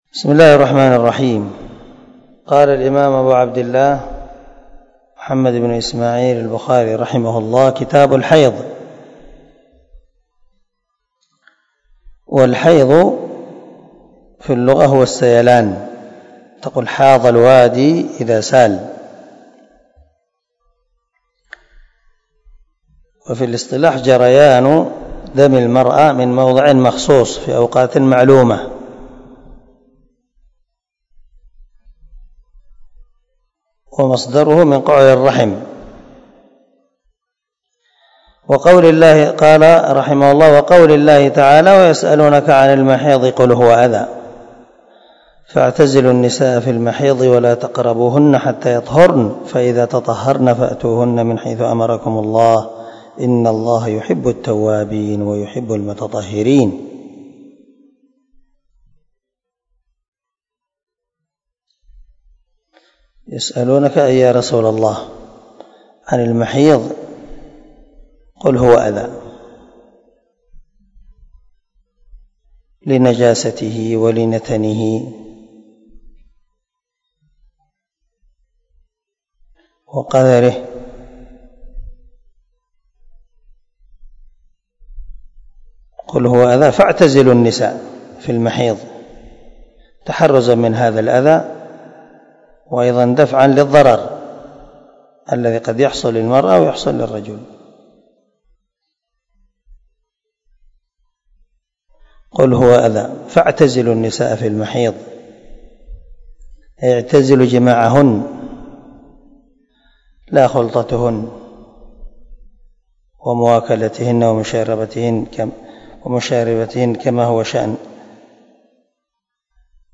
234الدرس 1 من شرح كتاب الحيض حديث رقم ( 294 ) من صحيح البخاري